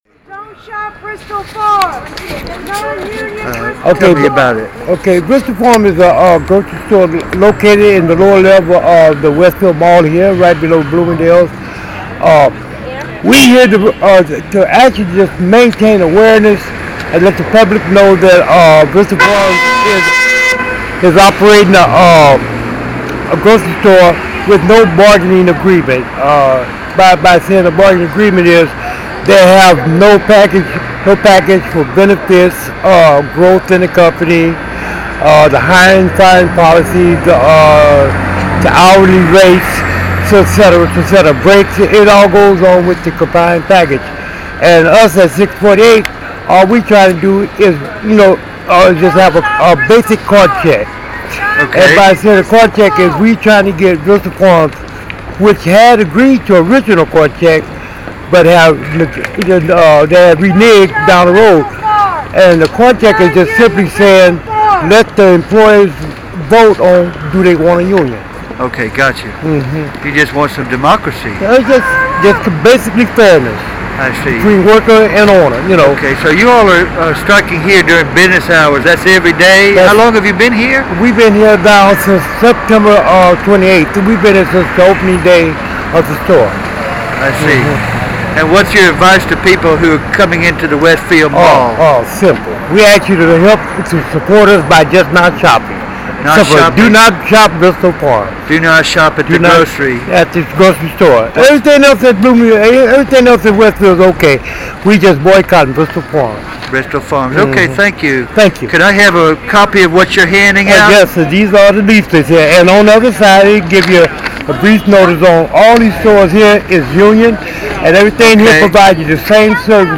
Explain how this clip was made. local_648_picket.mp3